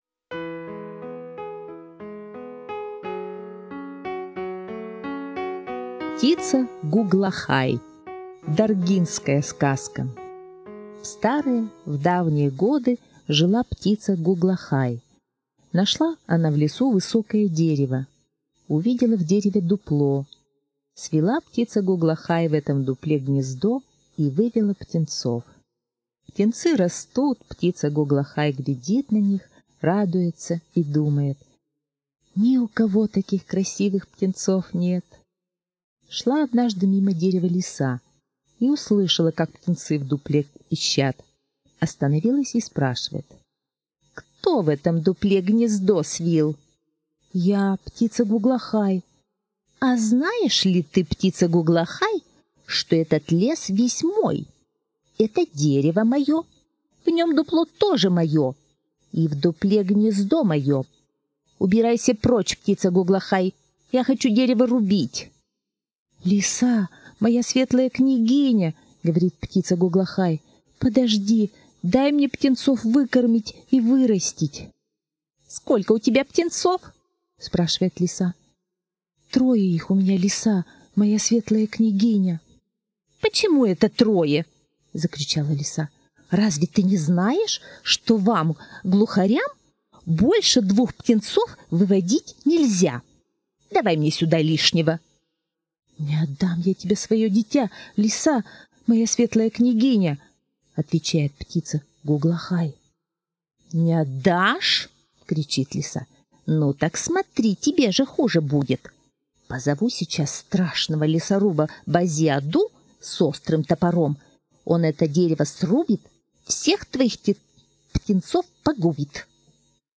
Аудиосказка «Птица Гуглахай»